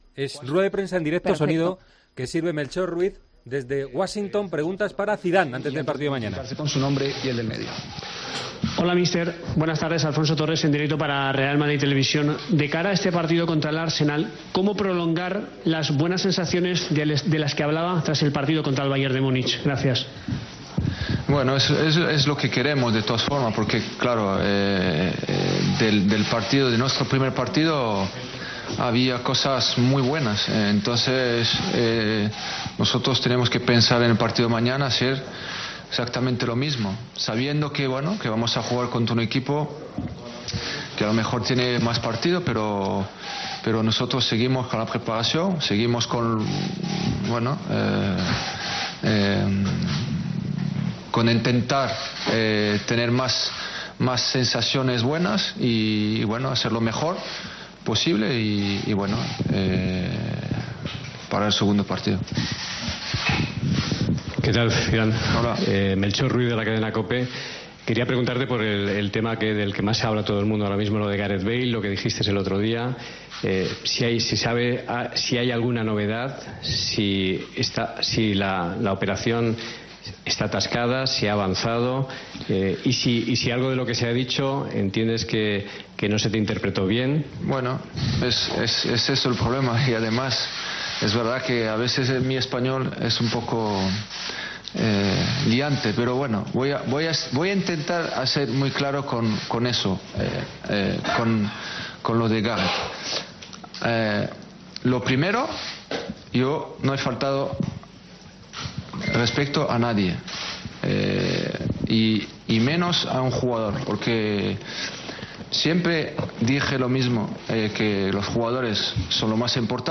Zinedine Zidane ha atendido a los medios en la rueda de prensa previa al encuentro que disputará el Real Madrid ante el Arsenal en la madrugada del martes al miércoles.